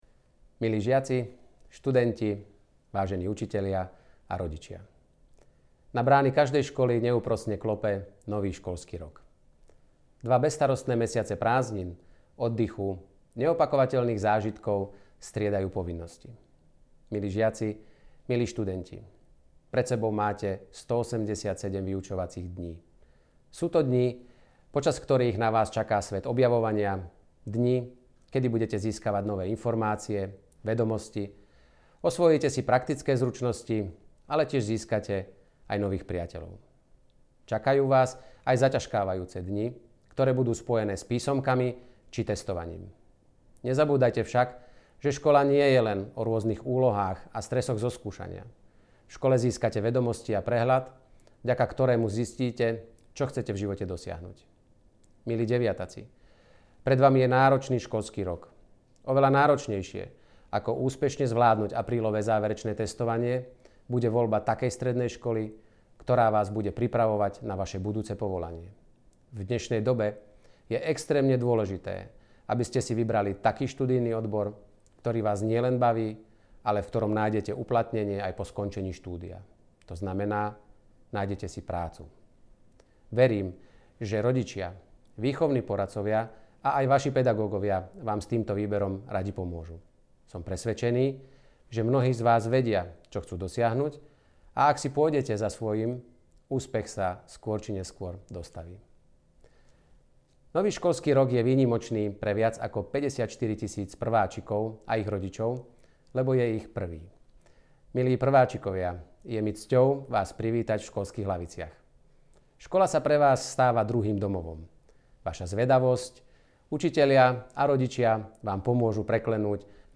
Príhovor ministra školstva Petra Pellegriniho k začiatku školského roka
Príhovor vo formáte mp3